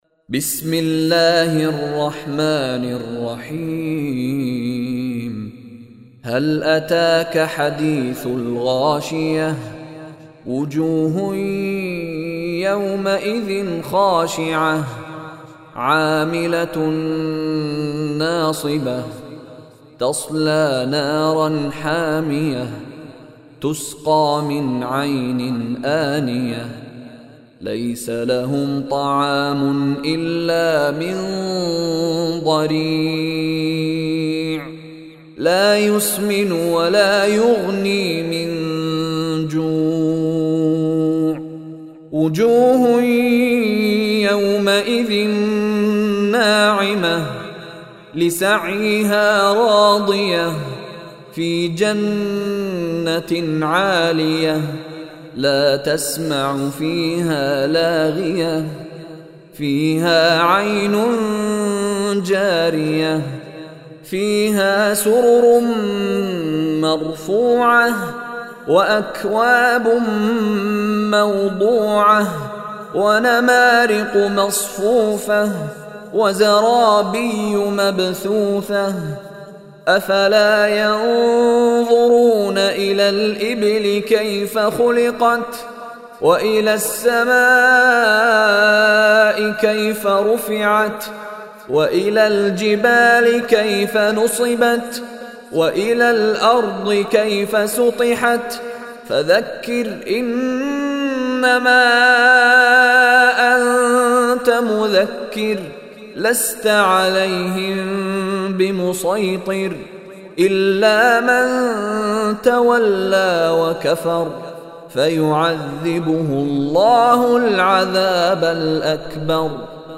Surah Ghashiyah Recitation by Mishary Rashid
Surah Ghashiyah is 88th chapter / surah of Holy Quran. Listen online and download beautiful recitation of Surah Ghashiyah in the voice of Sheikh Mishary Rashid Alafasy.